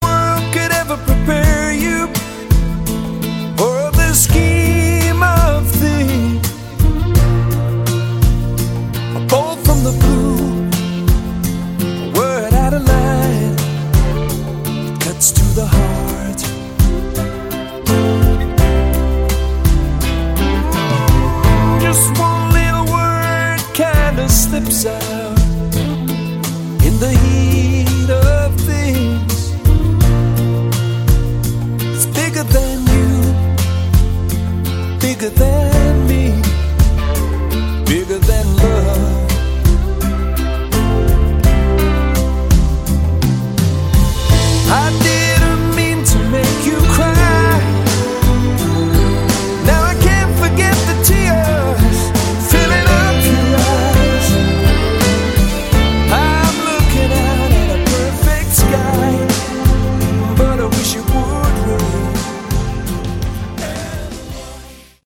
Category: Westcoast AOR
vocals, guitars
bass, vocals